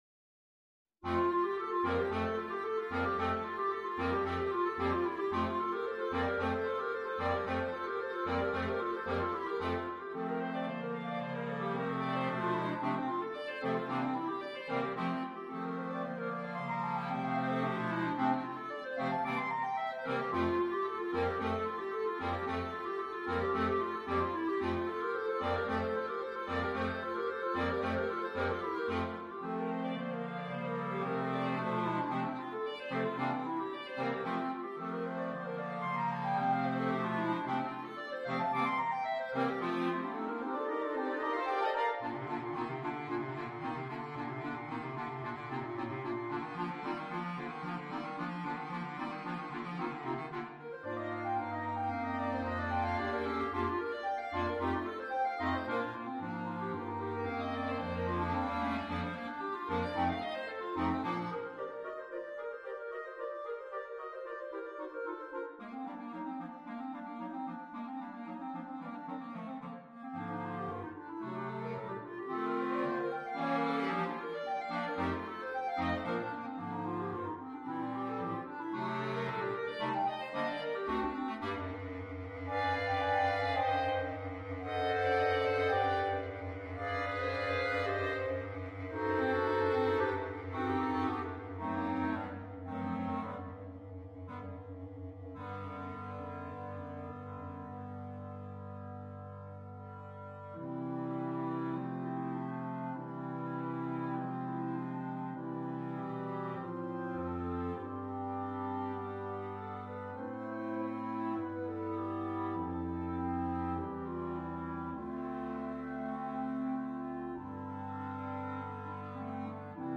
per coro di clarinetti